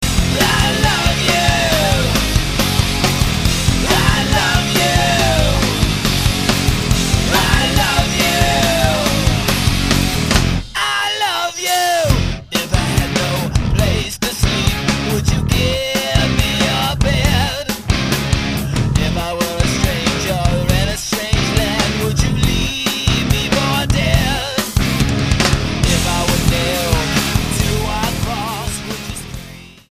STYLE: Hard Music
hard'n'heavy alternative rock